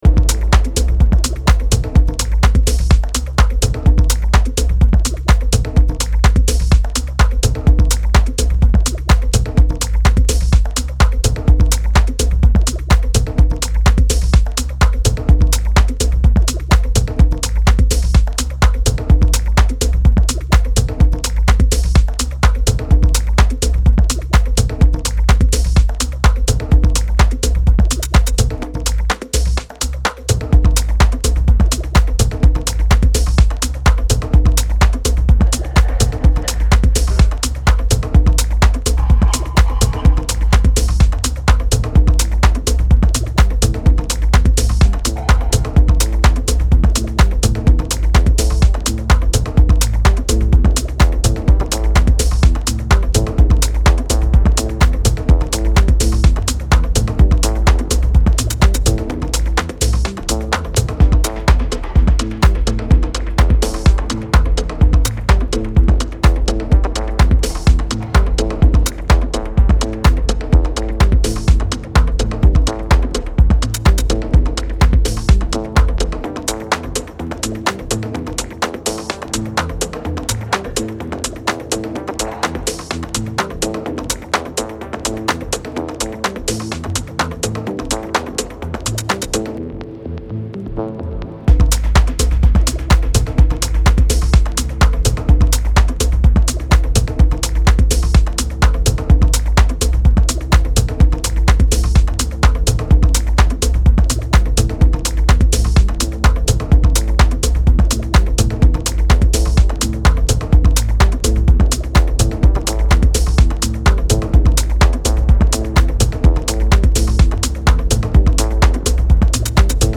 ここでは、繊細な音響処理が施されたダークでクールなミニマル・ハウスを全4曲展開。